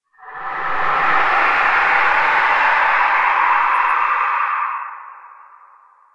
描述：由大量处理过的人声录音产生的无人机
Tag: 雄蜂 处理 语音